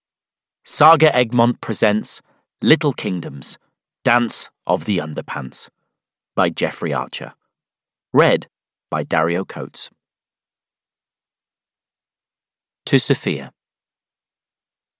Audiobook Little Kingdoms: Dance of the Underpants by Jeffrey Archer.
Ukázka z knihy